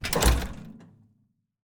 sounds / doors / metal / close.ogg
close.ogg